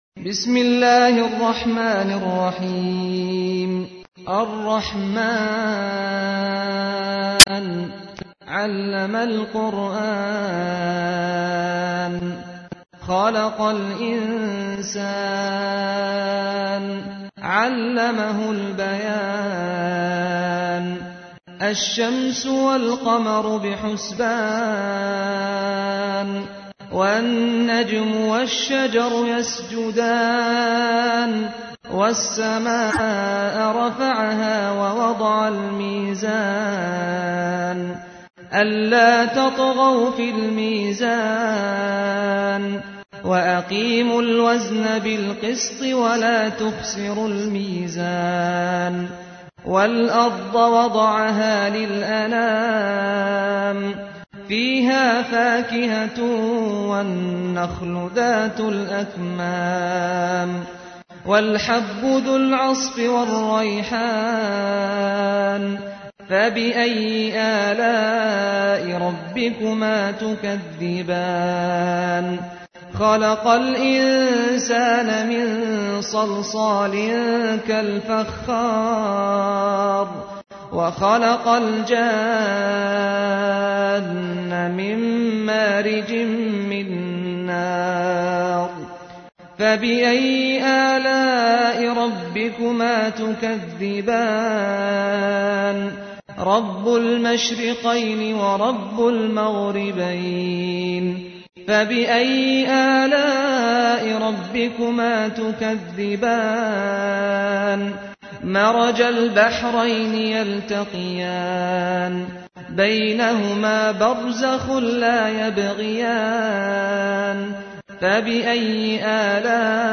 تحميل : 55. سورة الرحمن / القارئ سعد الغامدي / القرآن الكريم / موقع يا حسين